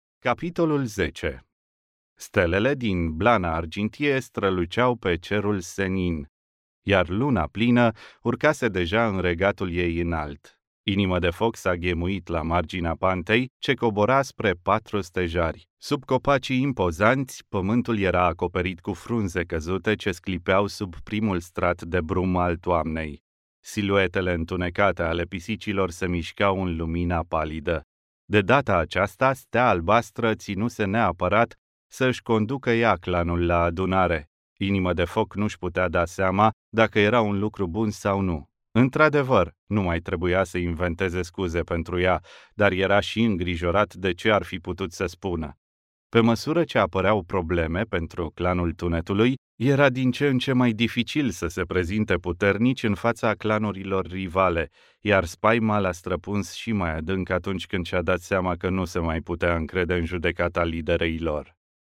Experienced Romanian male, native, voice talent
Romanian male - AudioBook sample